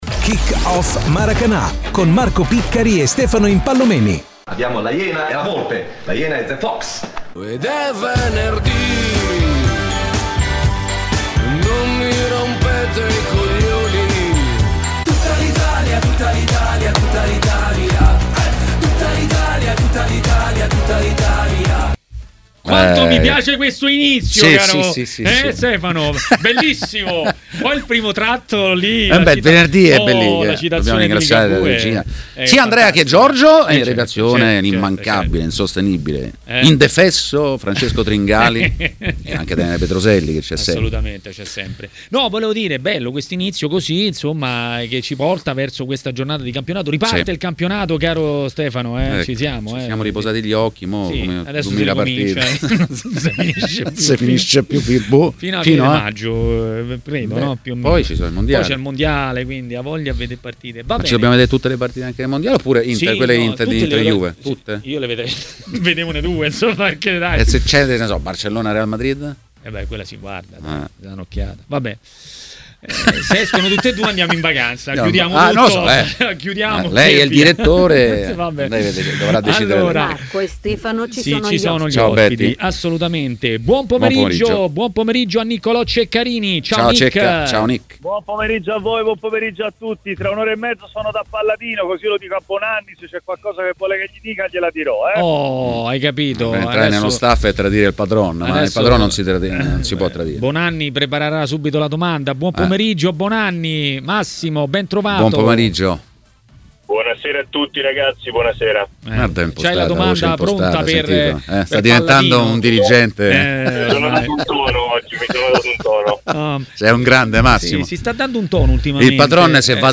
A parlare dei temi del giorno a TMW Radio, durante Maracanà